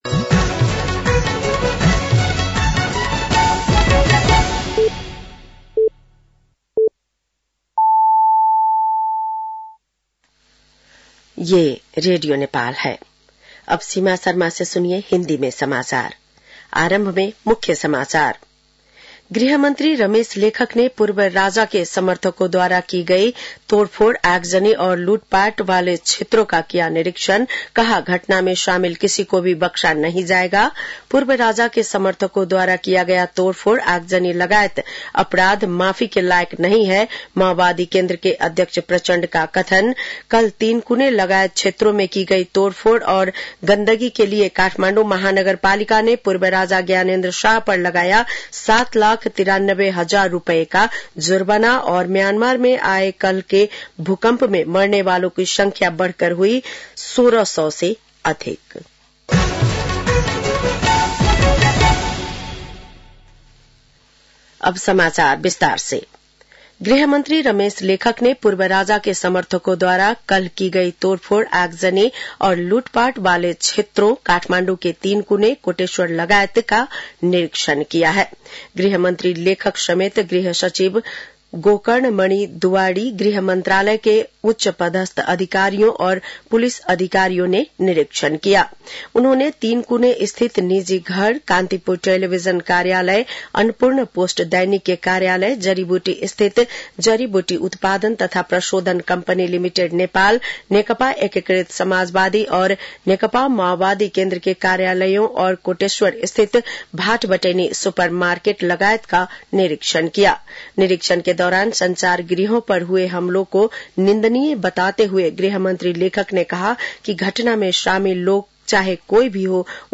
बेलुकी १० बजेको हिन्दी समाचार : १६ चैत , २०८१